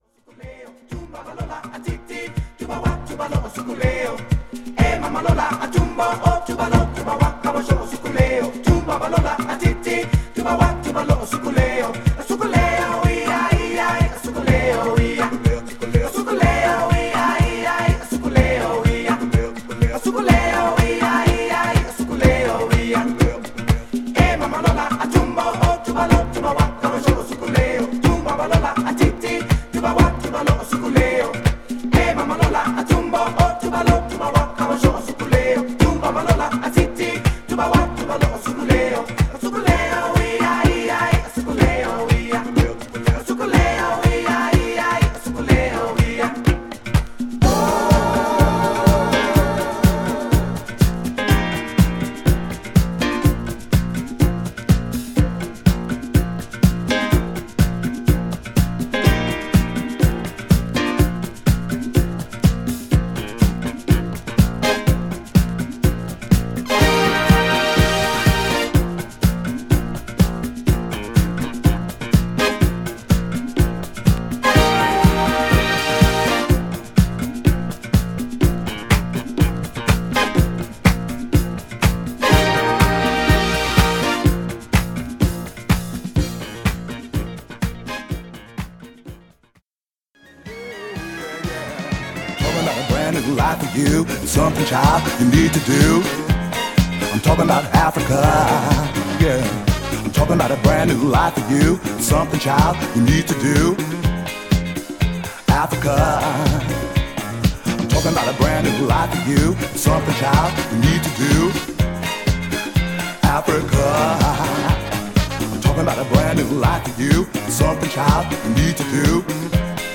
ITALO